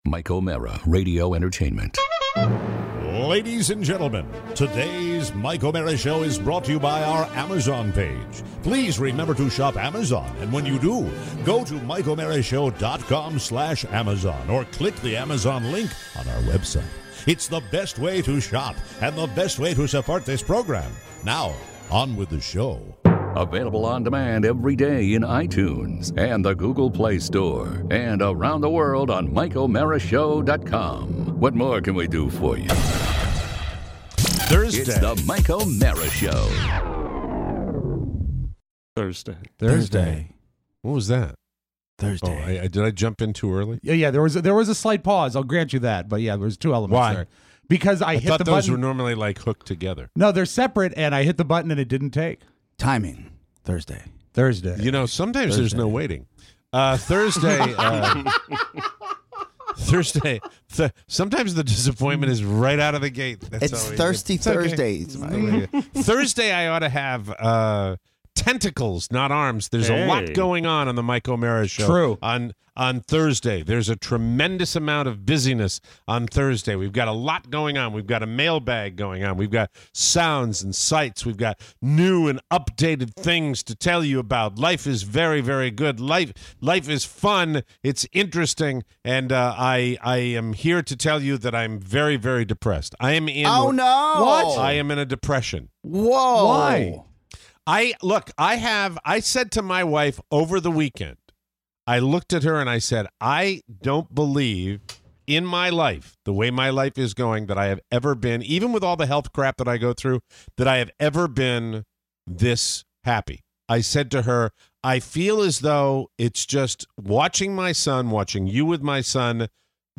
A surprise on the phones!